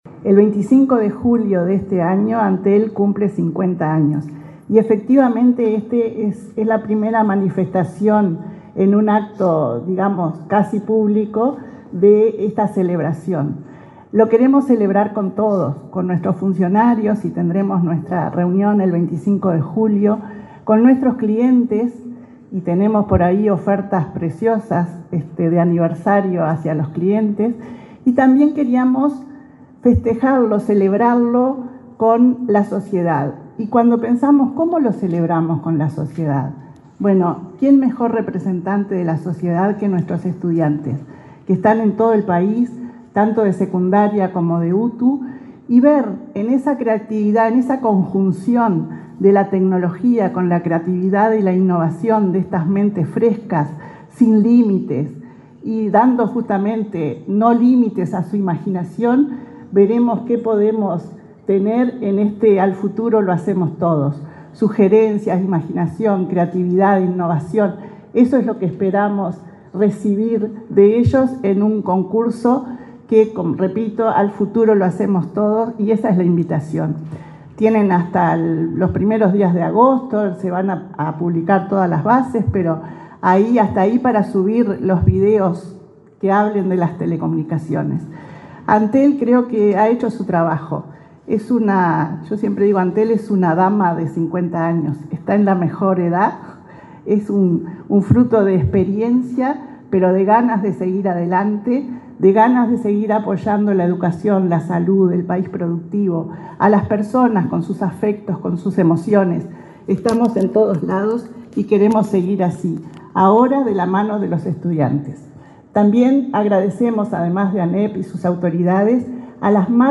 Palabras de autoridades en firma de acuerdo entre Antel y ANEP
La presidenta de Antel, Annabela Suburú, y la titular de la Administración Nacional de Educación Pública (ANEP), Virginia Cáceres, participaron en el